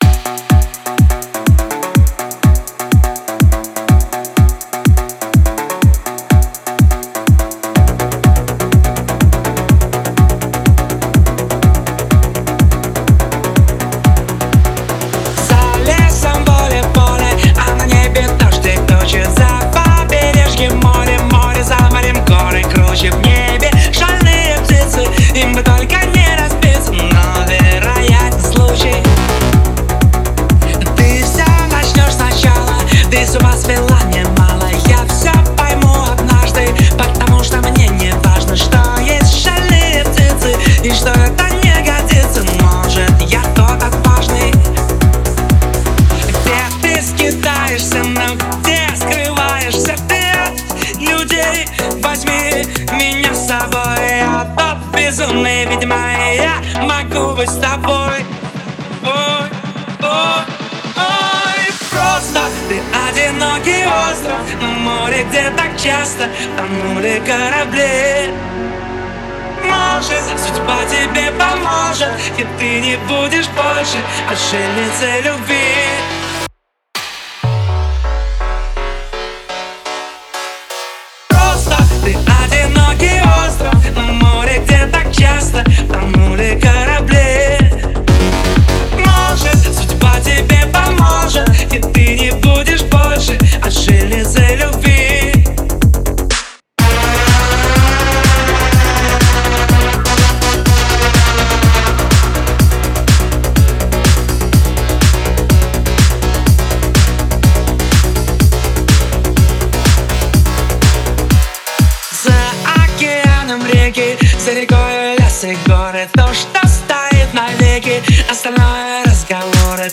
танцевальные песни , ремиксы